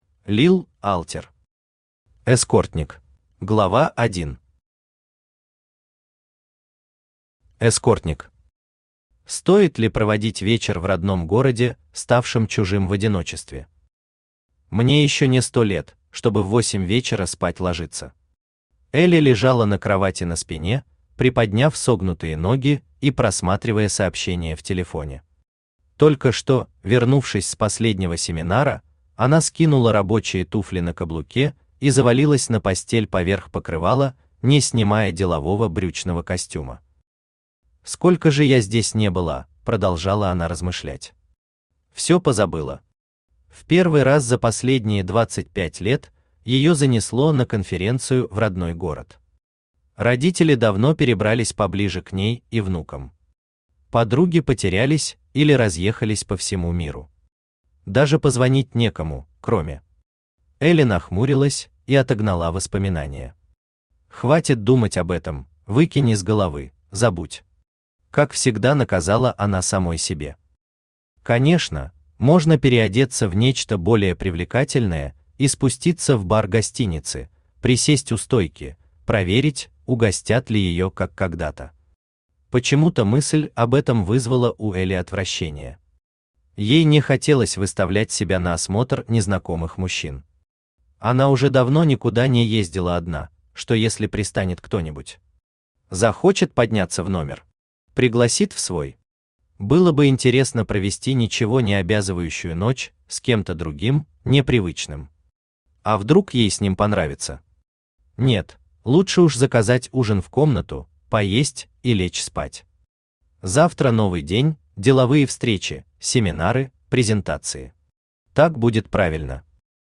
Aудиокнига Эскортник Автор Лил Алтер Читает аудиокнигу Авточтец ЛитРес.